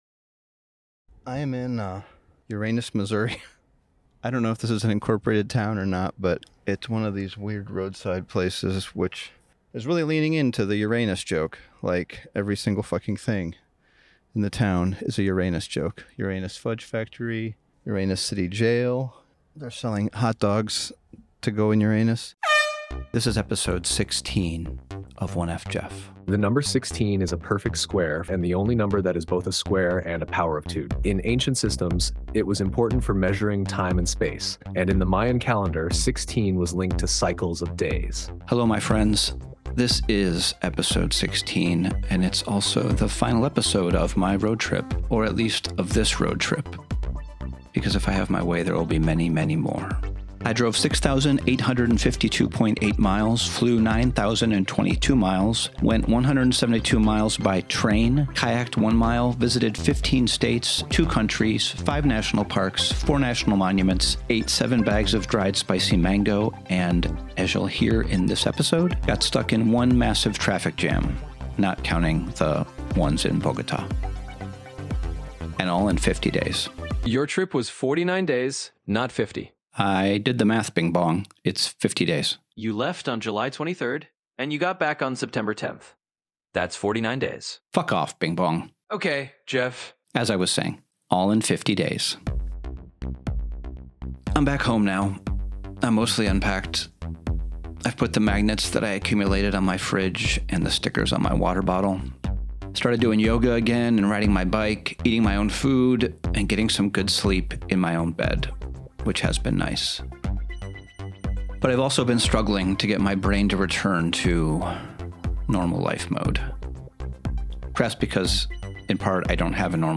Conversations with interesting humans, plus occasional unplanned solo rambles, surprise guests, and audio detritus from my archive.